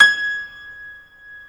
Index of /90_sSampleCDs/InVision Interactive - Lightware VOL-1 - Instruments & Percussions/GRAND PIANO1